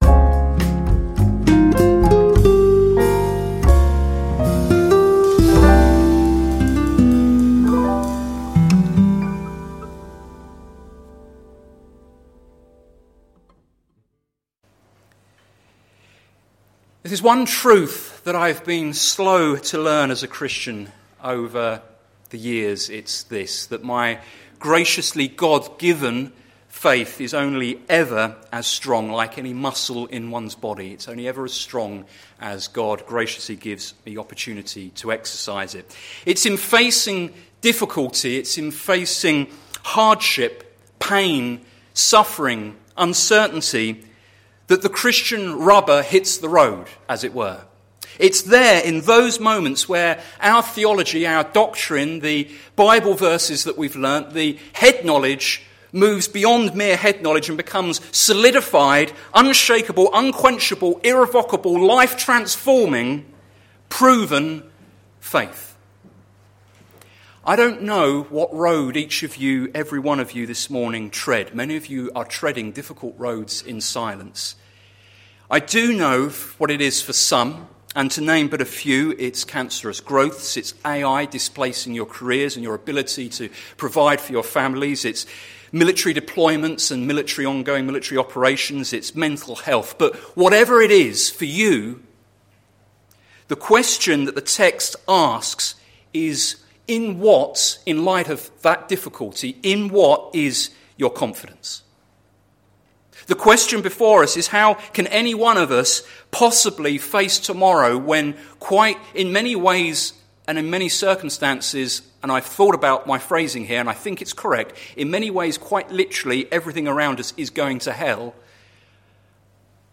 Sermon Series - Things Jesus said - plfc (Pound Lane Free Church, Isleham, Cambridgeshire)